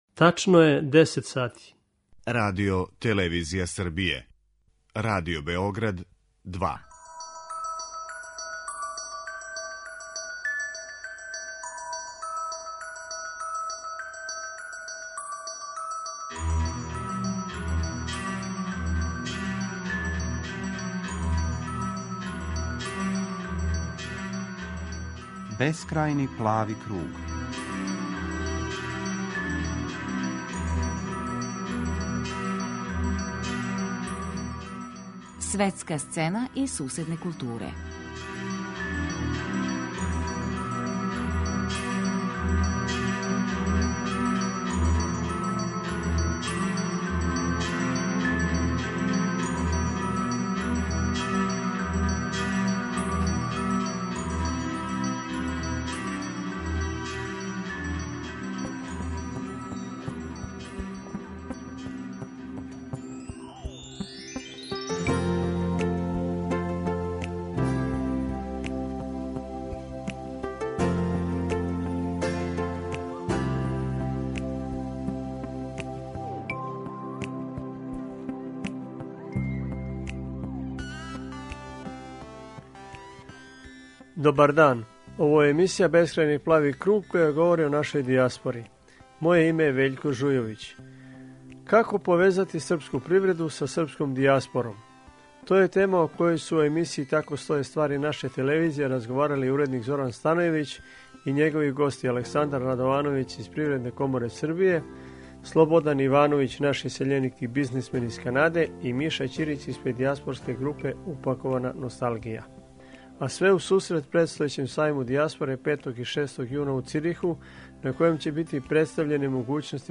Чућемо најзанимљивије делове разговора.